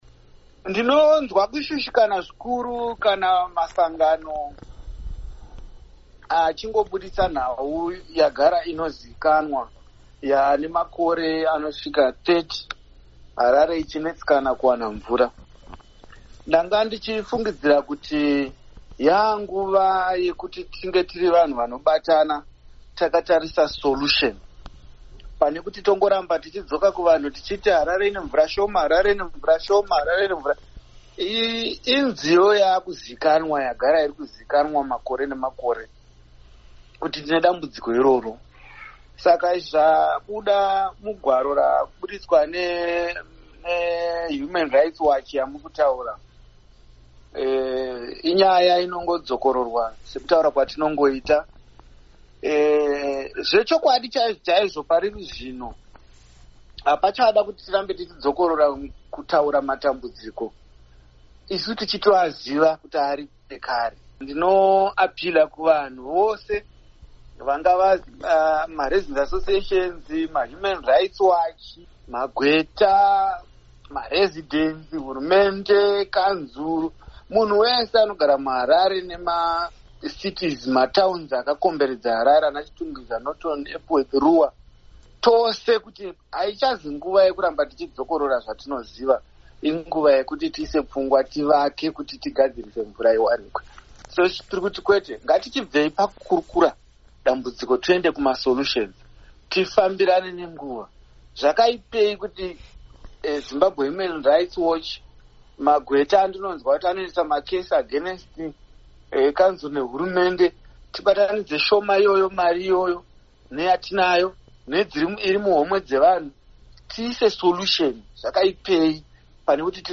Hurukuro naVaHerbert Gomba